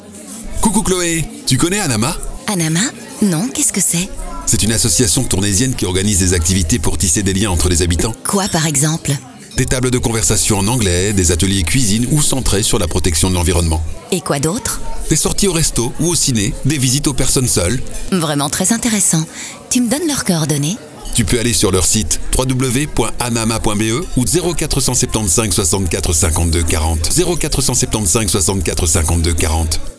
On a parlé d’Anama à la radio!